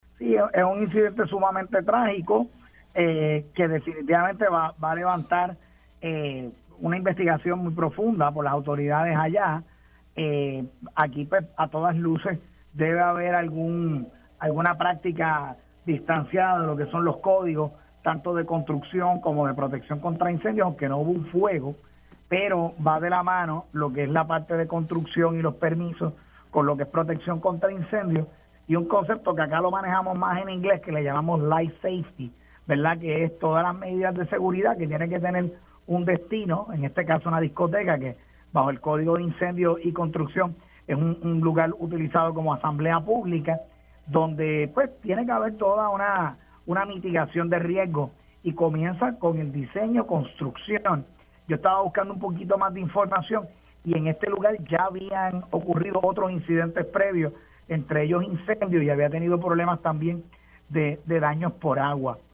El exjefe del Cuerpo de Bomberos de Puerto Rico, Ángel Crespo señaló en Pega’os en la Mañana que el colapso del techo en la discoteca Jet Set en Santo Domingo, República Dominicana no es el primer incidente en el local.